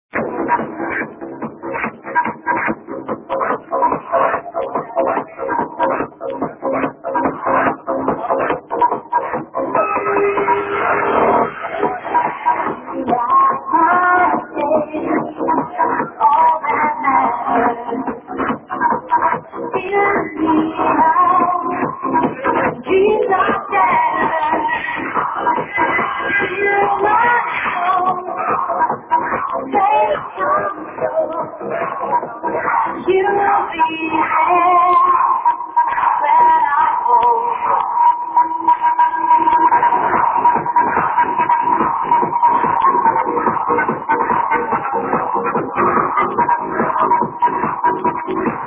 Has very nice vocals, and I have been looking for it in a long long time..